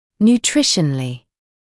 [njuː’trɪʃənəlɪ][нйуː’тришэнэли]питательно; с питательной точки зрения
nutritionally.mp3